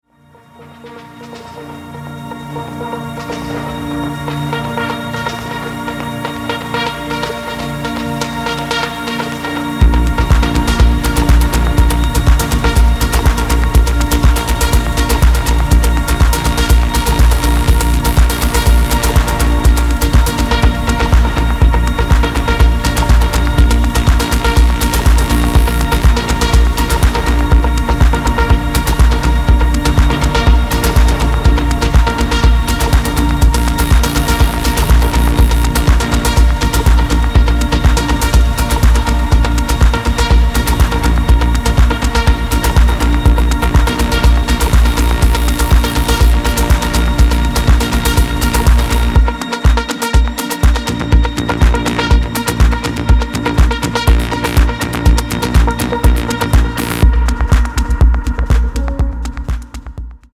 original motion picture score
entirely electronic and techno score